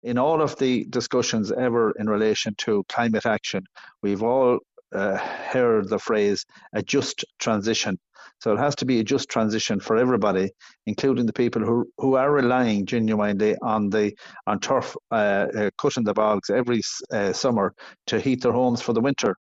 Independent Galway East TD Sean Canney says a lot of people rely on turf to heat their homes and have no viable alternative…………..